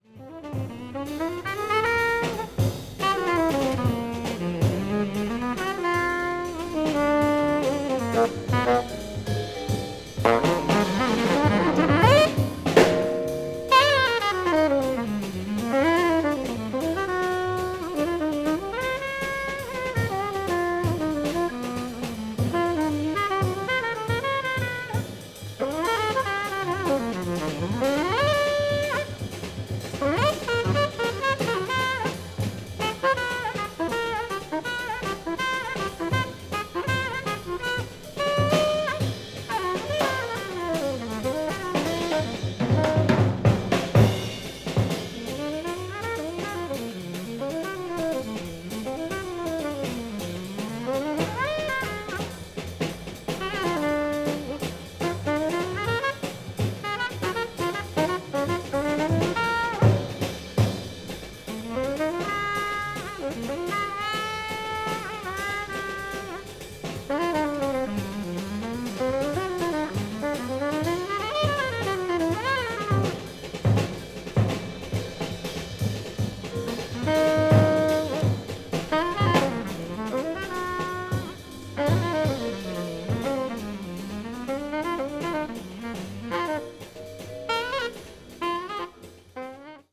bop   bossa jazz   cool jazz   modern jazz